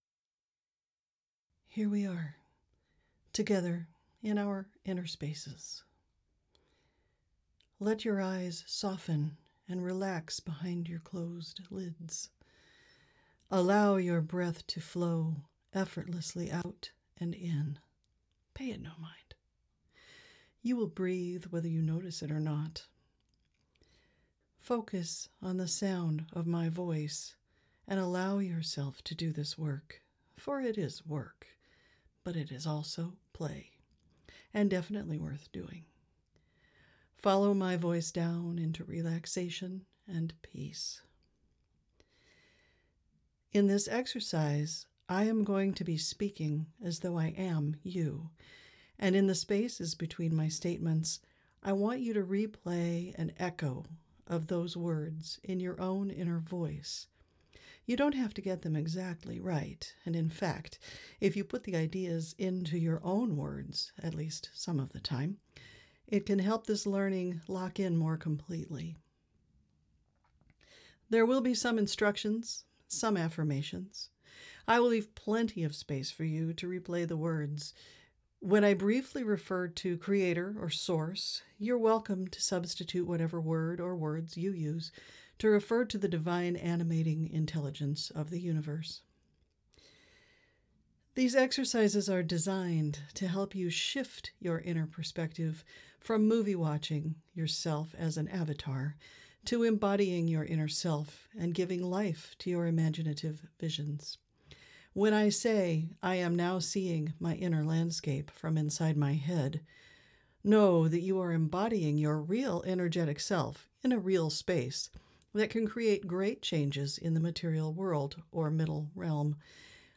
Experiencer Guided Visualization – SoundWorks
Wow, based on some feedback from last week’s post, I have decided to do a guided visualization to help us all start flexing that Experiencer muscle!